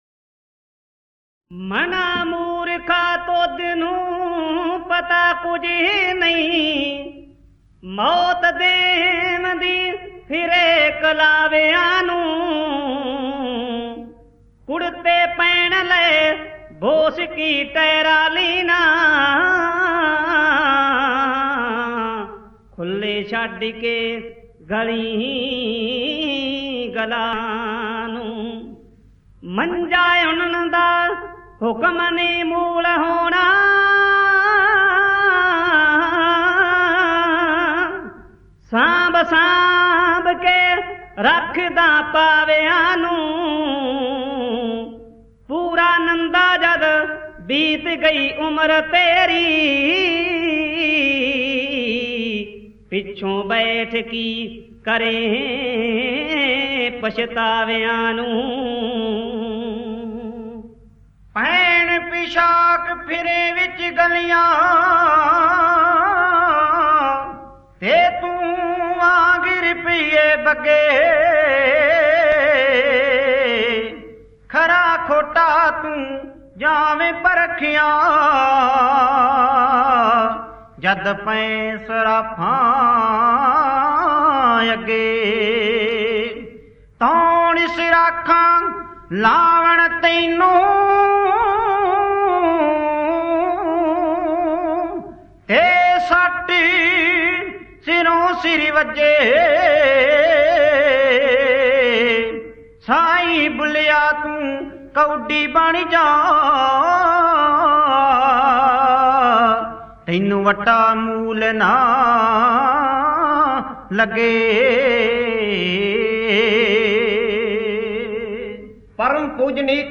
Genre: Kavishr